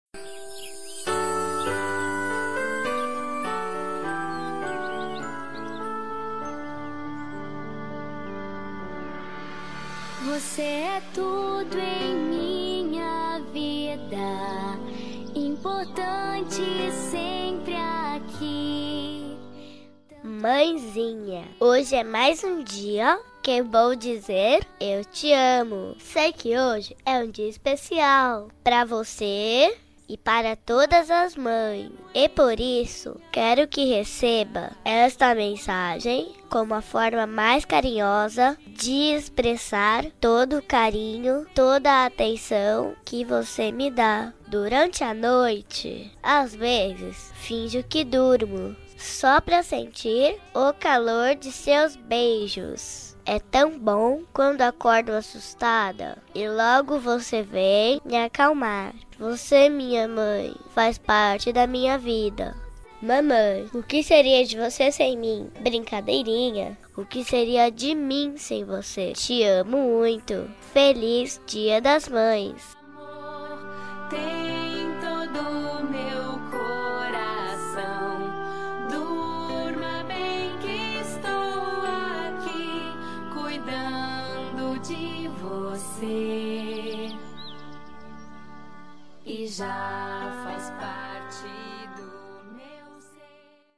Voz Menina